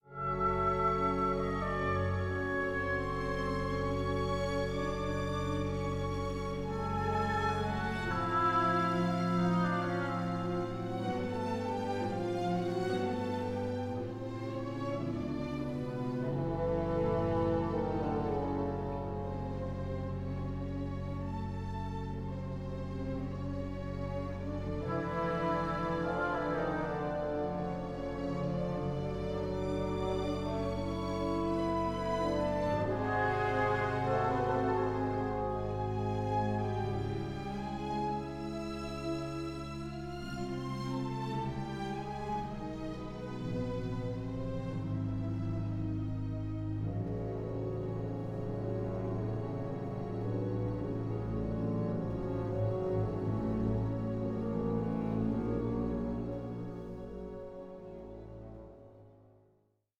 tone poem
it’s a bold, optimistic and passionate work